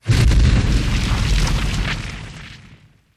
Flame Burst More Fire On End. Fire Burn.